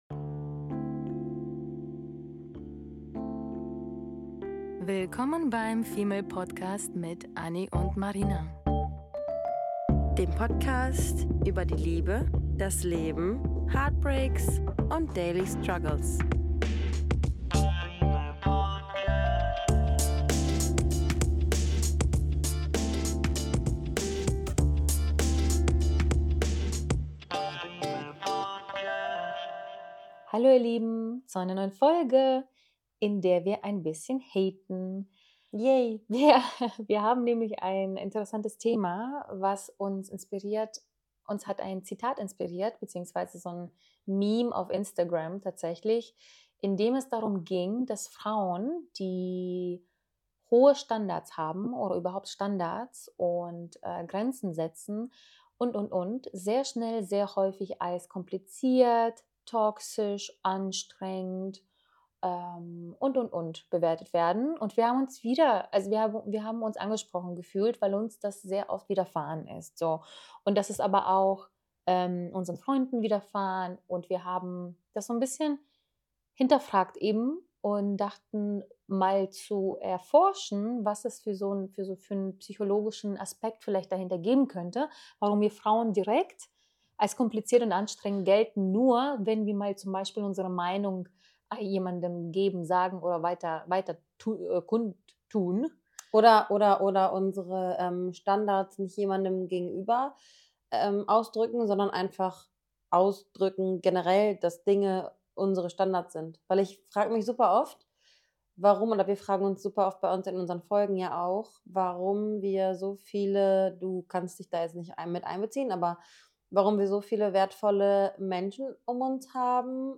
… continue reading 239 episoder # Fitness # Gesellschaft # Beziehung # Gespräche # Sexualität # Female